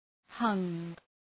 Προφορά
{hʌŋ} – αόρ. του ‘hang’